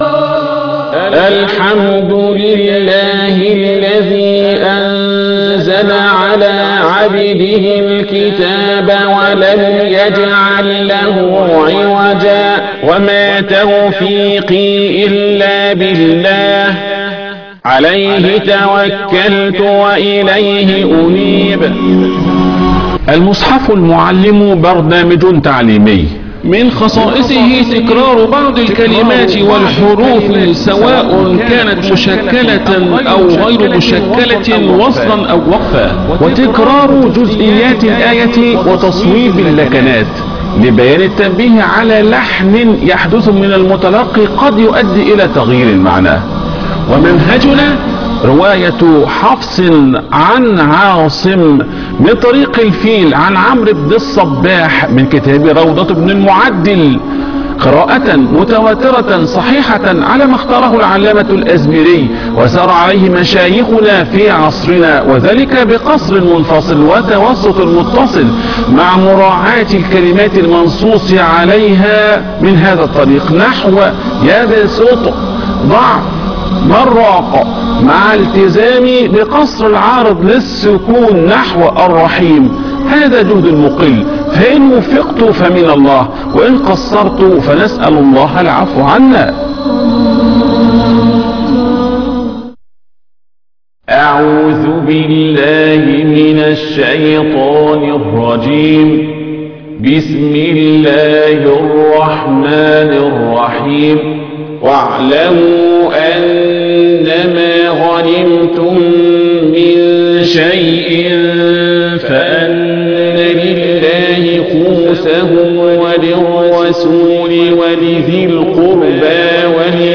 المصحف المعلم